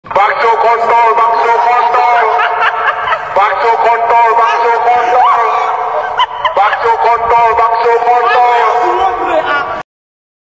Efek Suara Meme Bakso Kontol
Kategori: Suara viral
Keterangan: Sound Effect Bakso Kontol (Slow & Reverb) viral digunakan untuk edit video lucu.